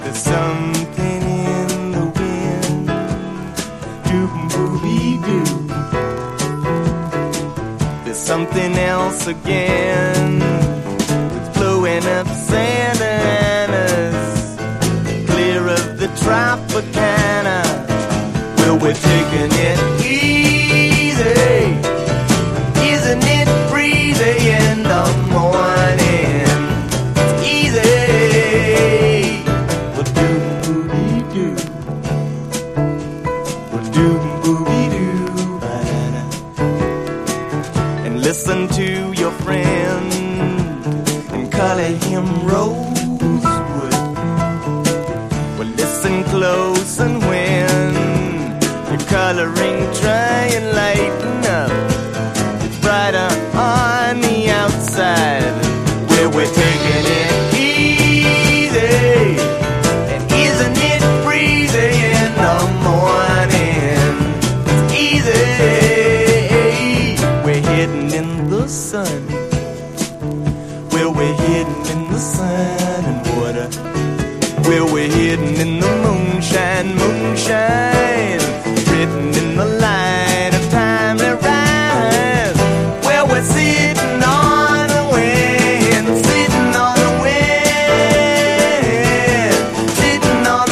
陽だまりメロウ・フォークS.S.W.必携盤！
陽だまりピアノ・ポップ